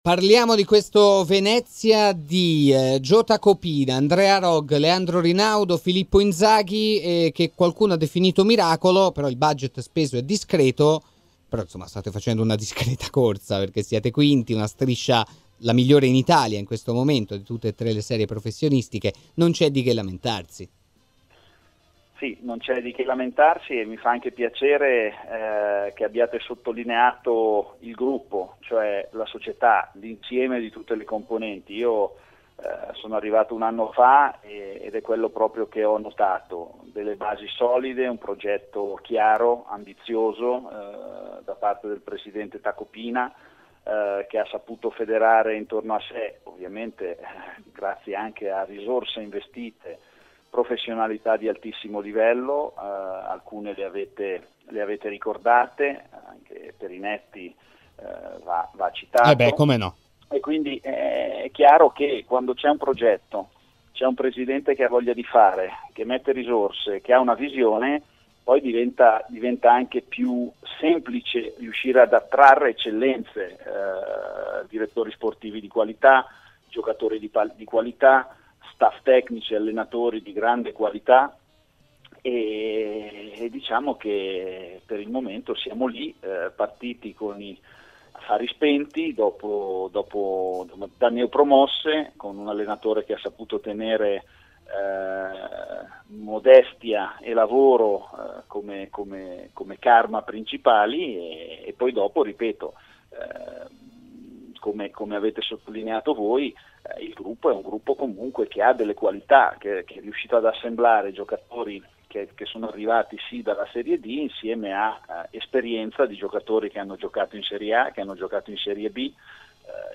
in diretta al Live Show di RMC Sport: